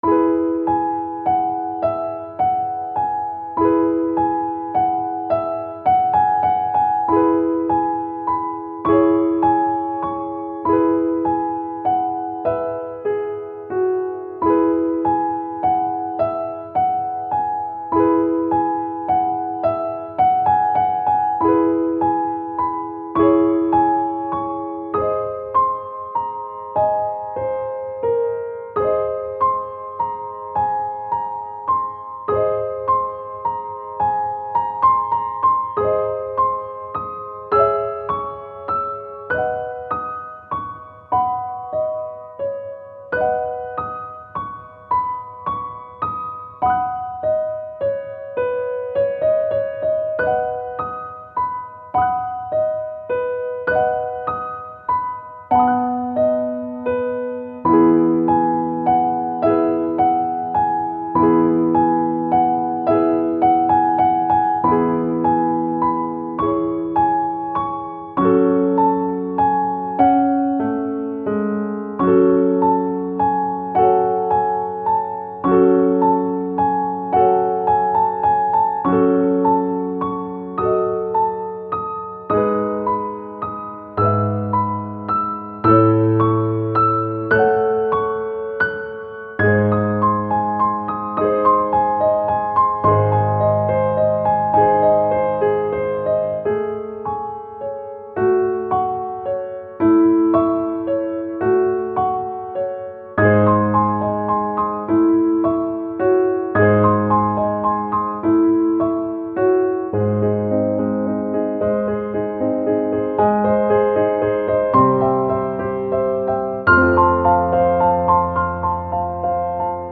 ♪サウンドプログラマ制作の高品質クラシックピアノ。
(しっとりピアノ版)